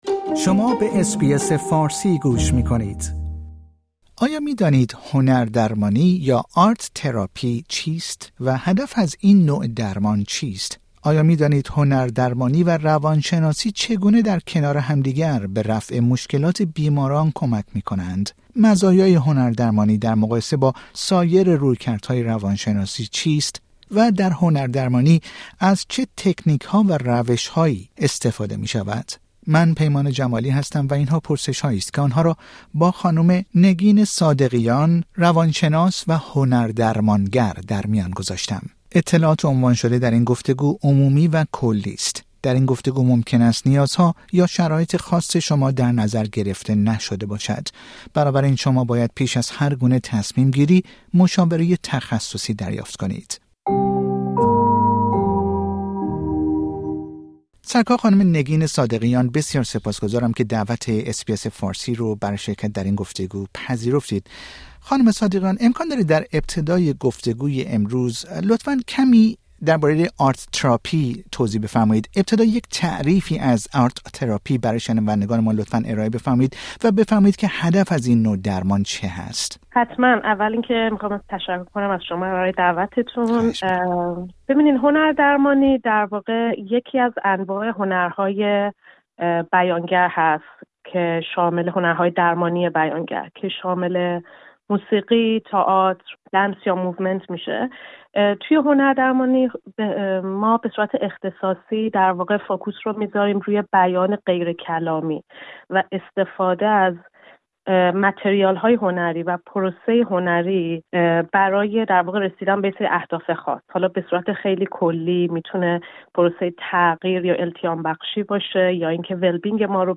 در گفتگو با اس بی اس فارسی به این پرسش ها پاسخ می دهد.